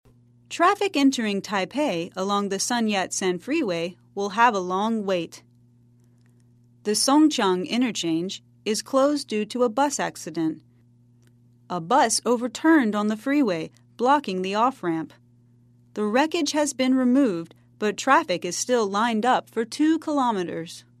在线英语听力室赖世雄英语新闻听力通 第83期:公交车事故致通道封闭的听力文件下载,本栏目网络全球各类趣味新闻，并为大家提供原声朗读与对应双语字幕，篇幅虽然精短，词汇量却足够丰富，是各层次英语学习者学习实用听力、口语的精品资源。